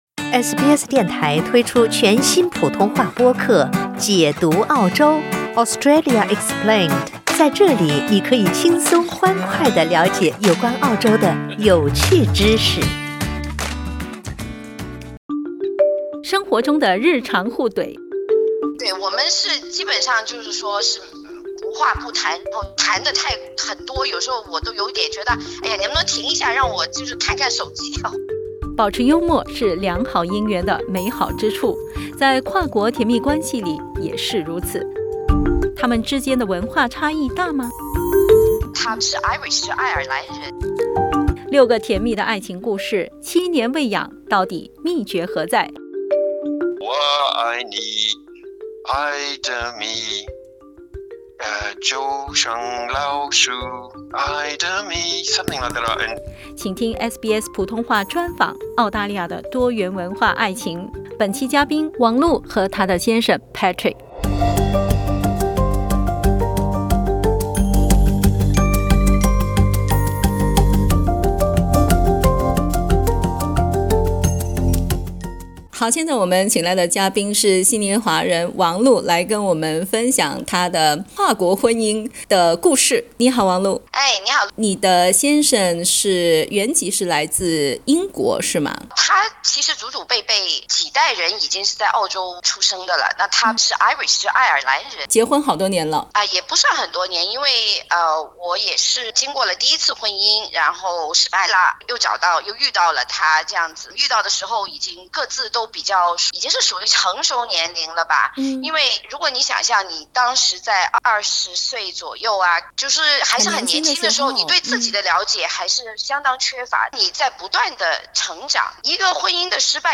六个甜蜜的爱情故事，七年未痒，到底秘诀何在？SBS普通话专访 《澳大利亚的多元文化爱情》为你解密。